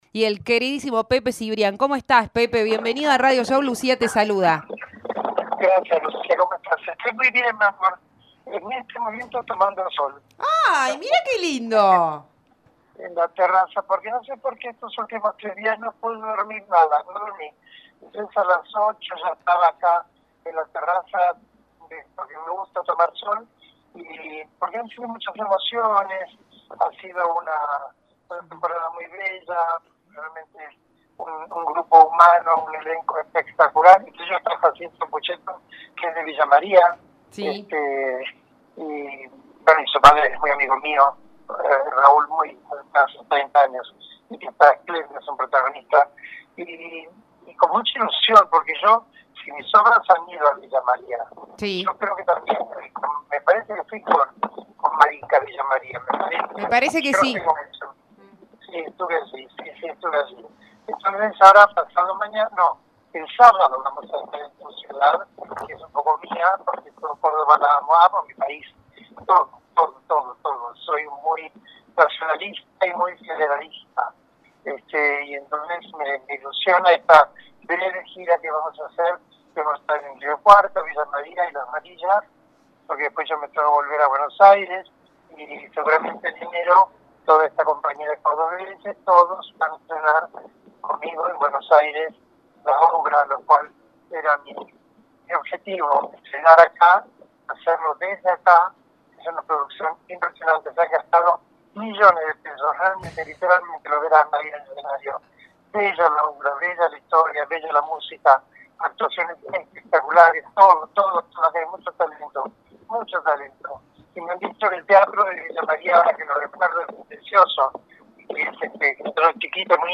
El artista conversó en la previa con Radio Show.